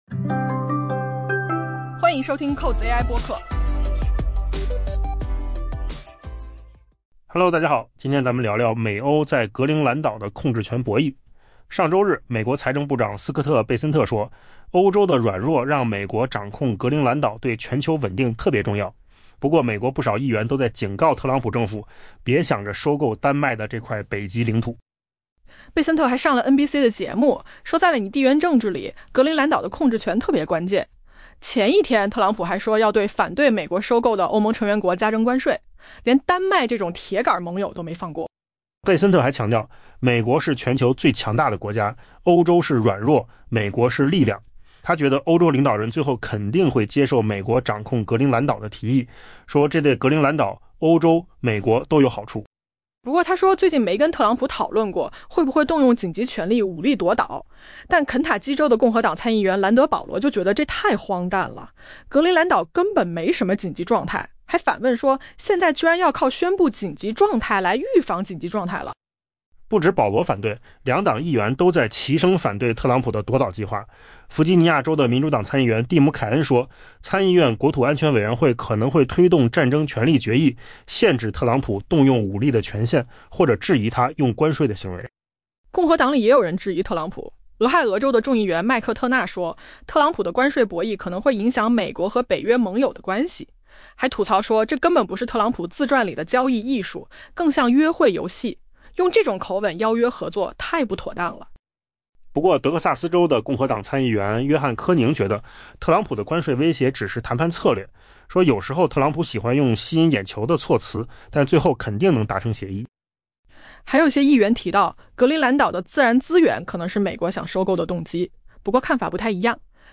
AI播客：换个方式听新闻 下载mp3
音频由扣子空间